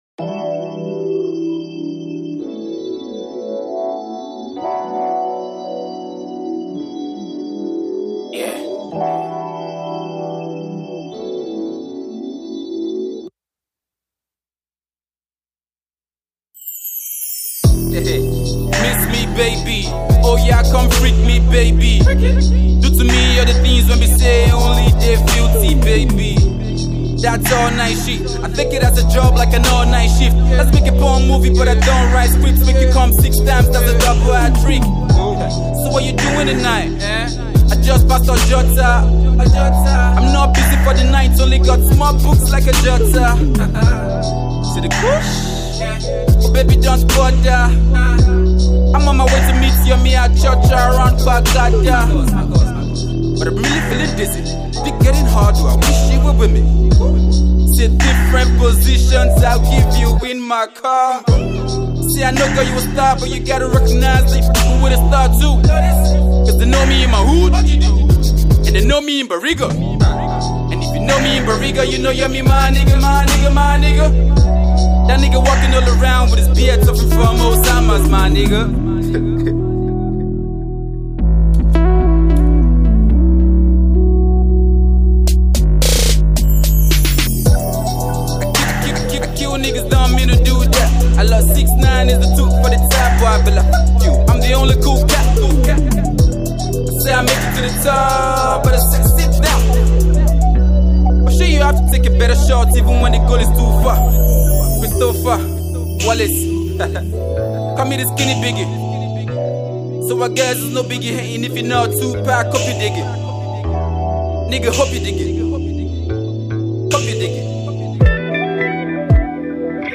the versatile rapper